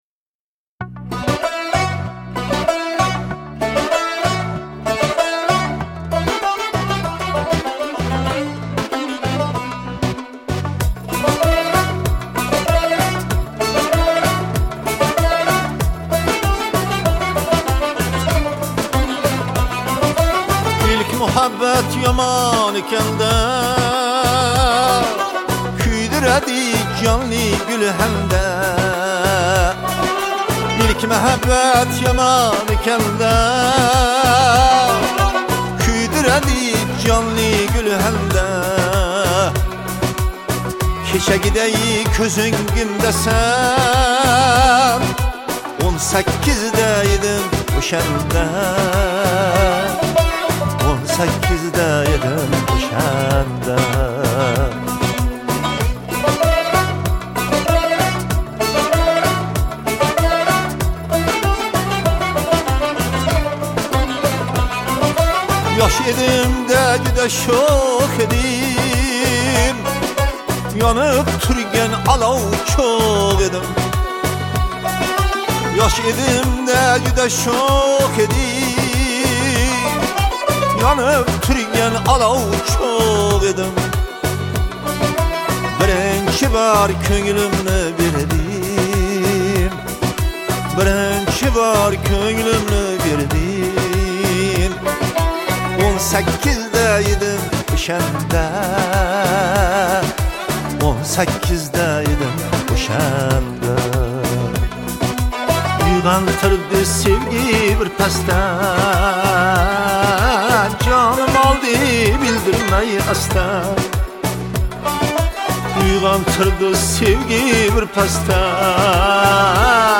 Мусиқа ва тарона Ўзбекистон мусиқаси